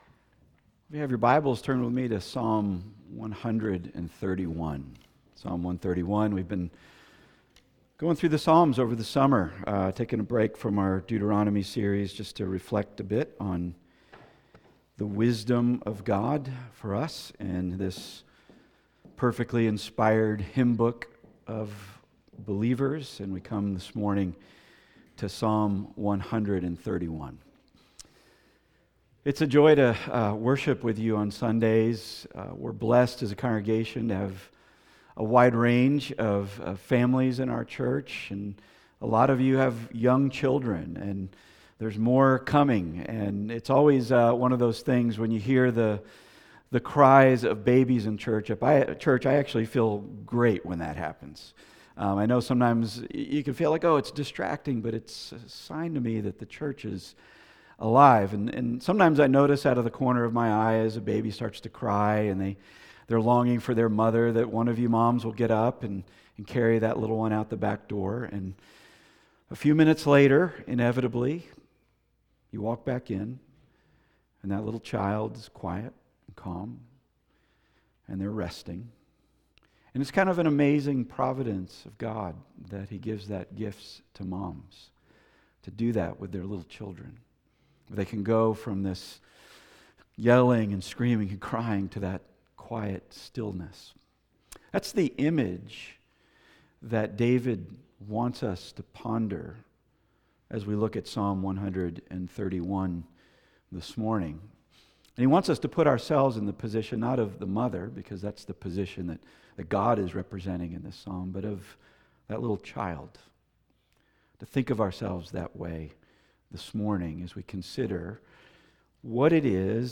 Passage: Psalm 131 Service Type: Weekly Sunday